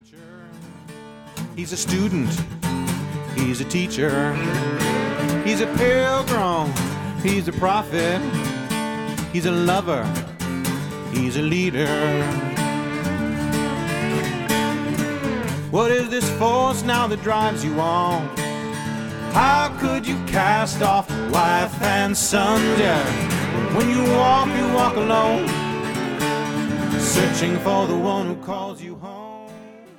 folk sounding lyrical voice
combined with his performance artistry on guitar